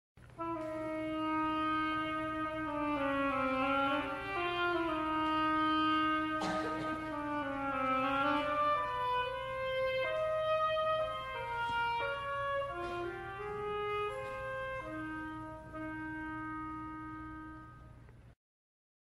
• Simfoniskā pasaka "Pēterītis un vilks" (SR) Saklausa mūzikas instrumentiem atbilstošus tēlus